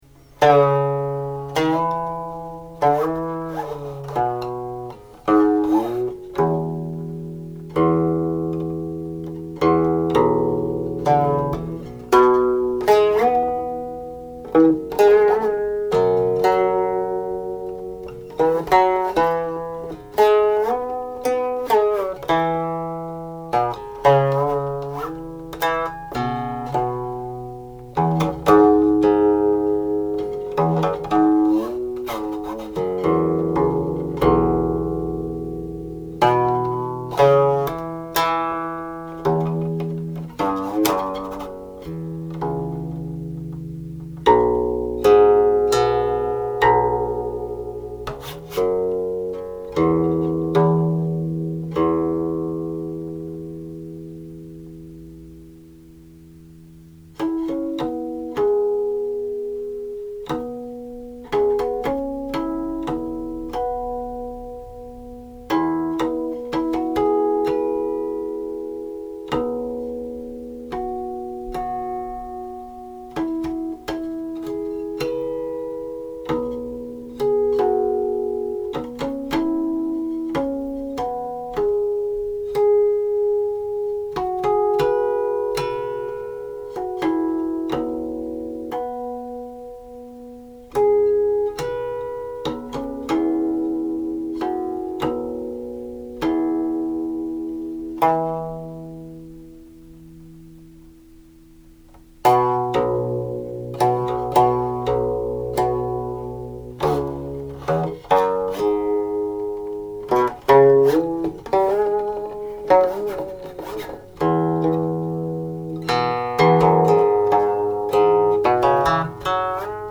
The melody is in three sections.
02.47       Closing harmonics